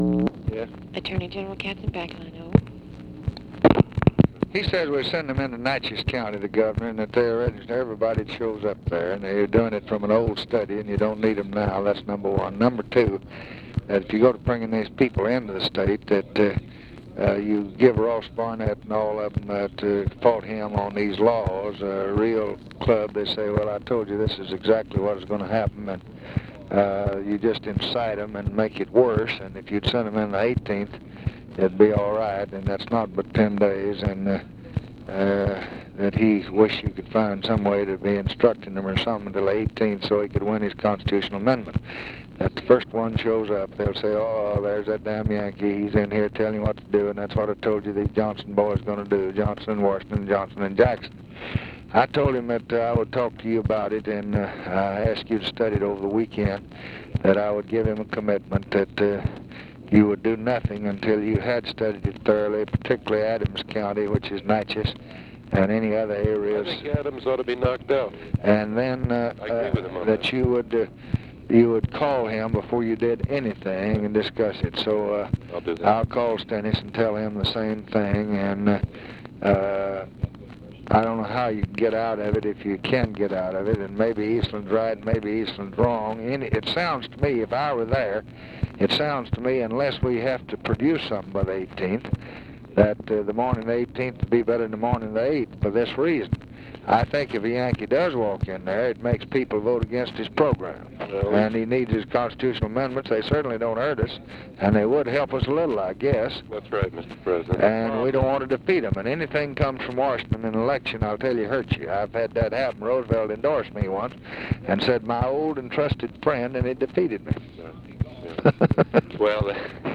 Conversation with NICHOLAS KATZENBACH, August 6, 1965
Secret White House Tapes